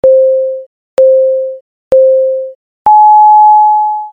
5-4.時報（ポ、ポ、ポ、ポーン！）
「ポ、ポ、ポ、ポーン！」という時報です。
jiho.mp3